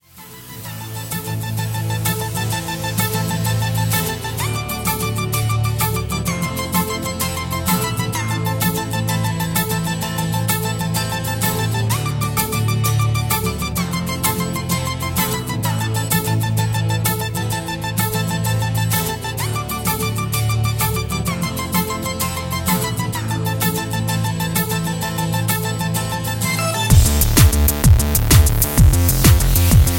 Backing track files: 2000s (3150)